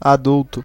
Ääntäminen
IPA : /ˈæd.ʌlt/